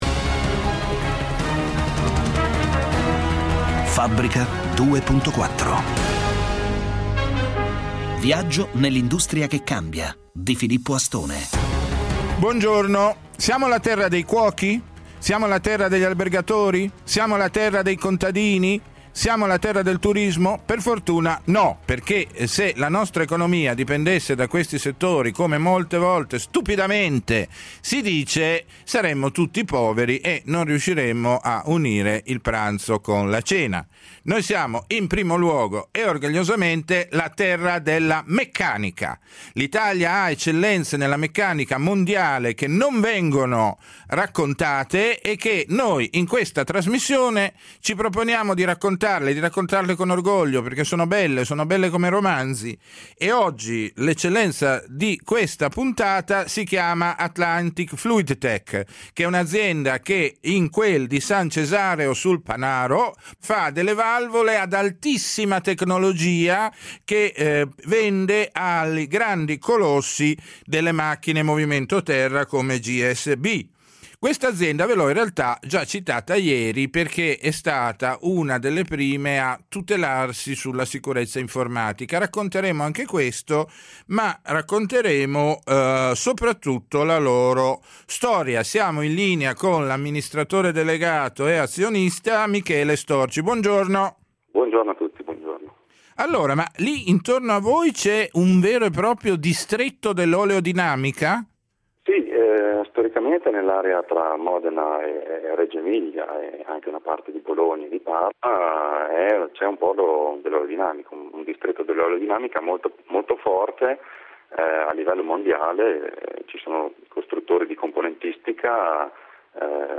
Intervista a Radio24